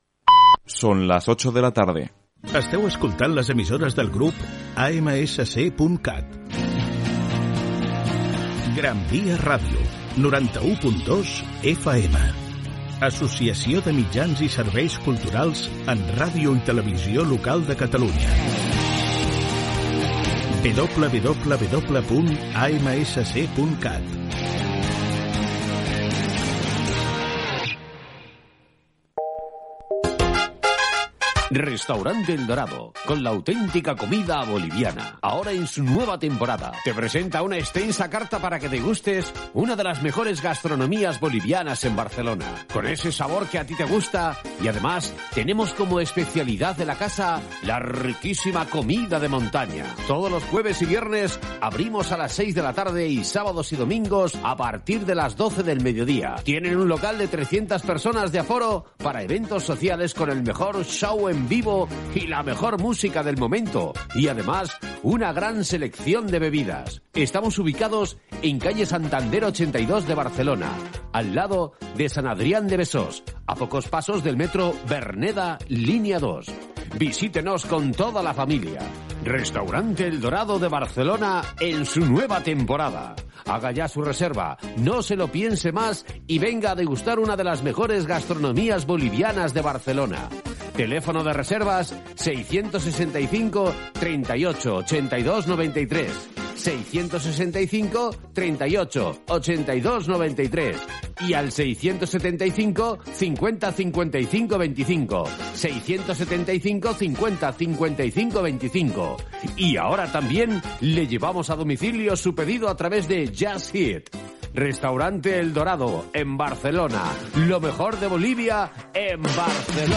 Indicatiu de l'emissora, publicitat, promoció i careta del programa. Hora, data i presentació amb esment de totes les formes com es pot escoltar l'emissora.
FM